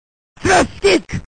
fatal1gen-slashkick.mp3